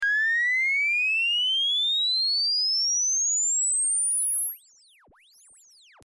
fg 117 aliasing sweep.mp3